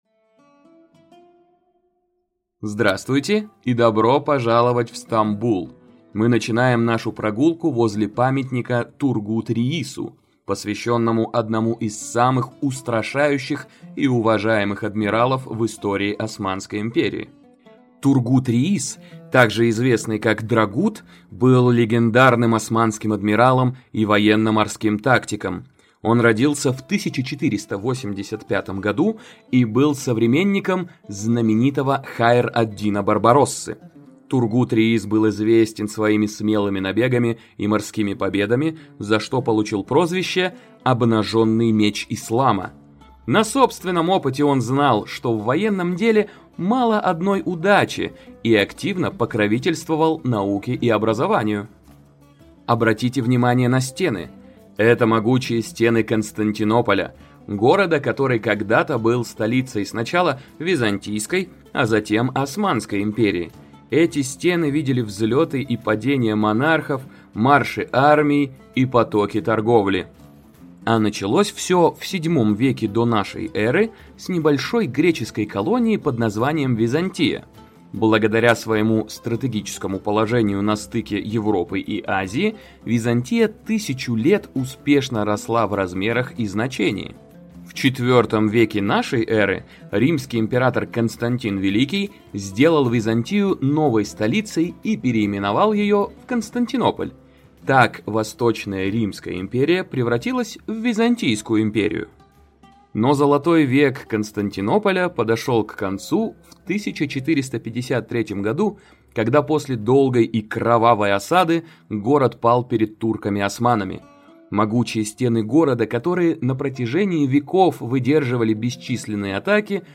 Аудиогид по Стамбулу на русском языке от TouringBee